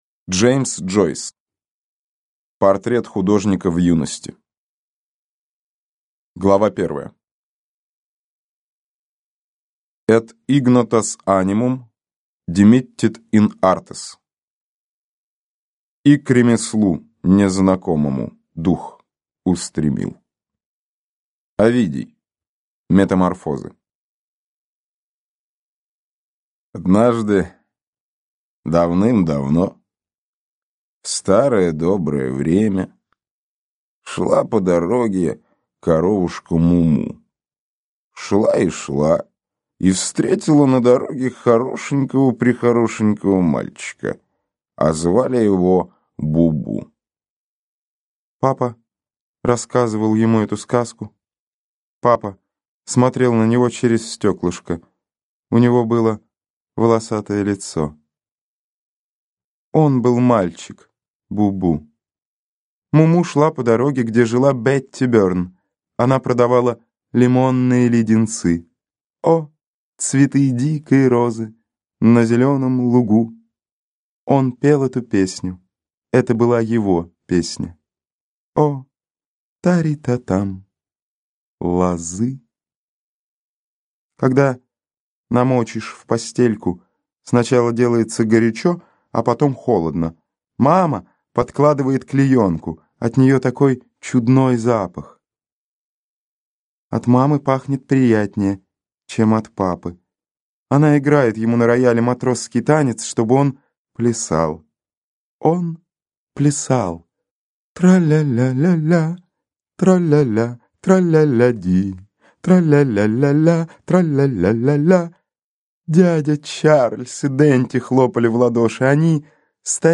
Аудиокнига Портрет художника в юности | Библиотека аудиокниг